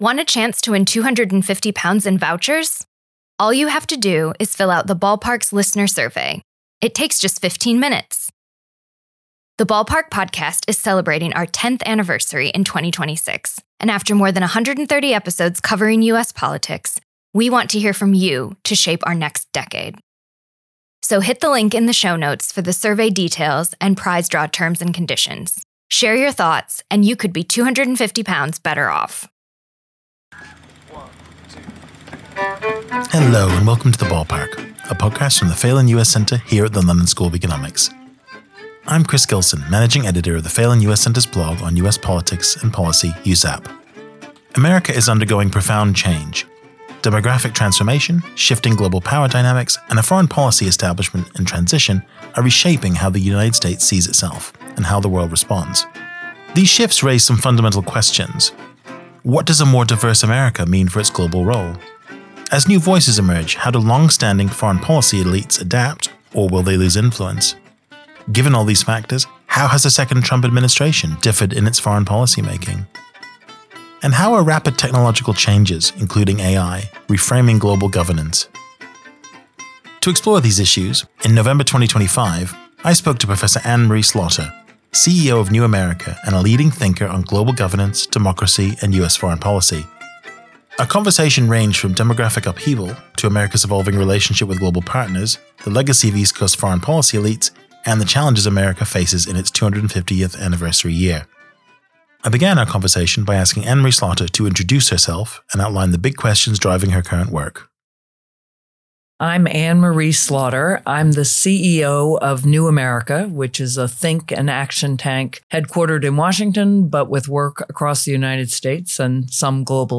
The conversation ranged from demographic upheaval to America’s evolving relationship with global partners, the legacy of East Coast foreign policy elites, and the challenges America faces in its 250th anniversary year.